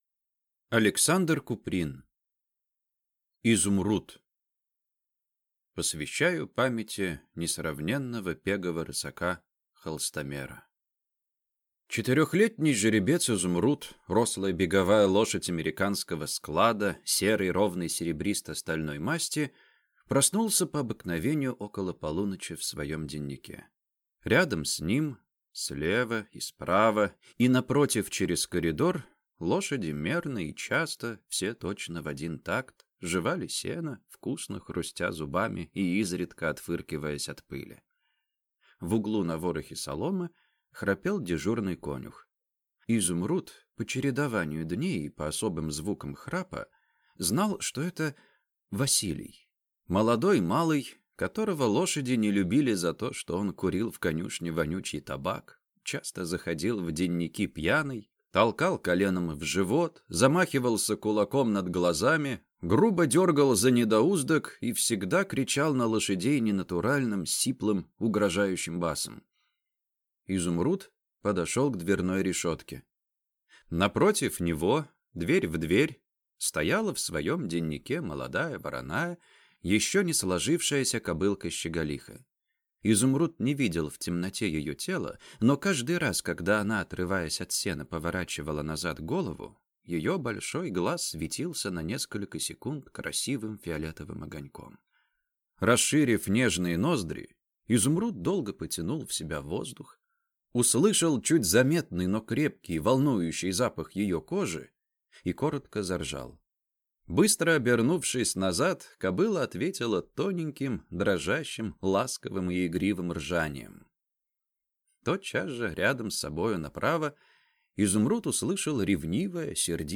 Аудиокнига Изумруд